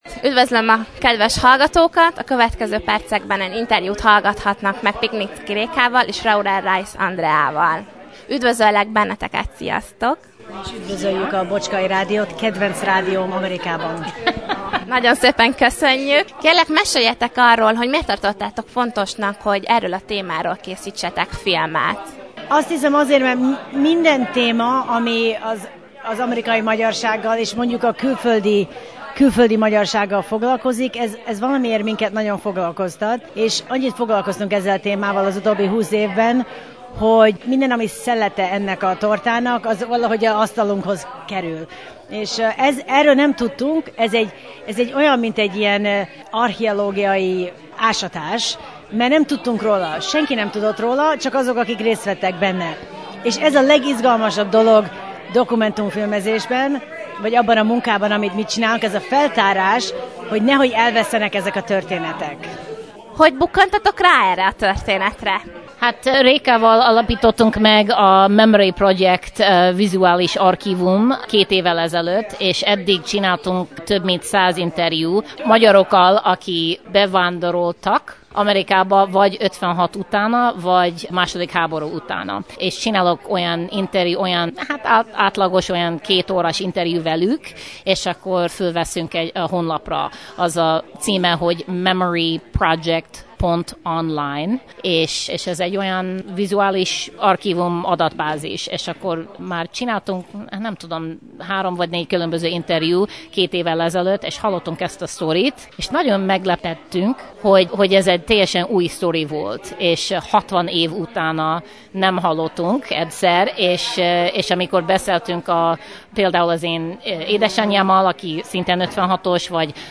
Az eseményen a Bocskai Rádió is részt vett.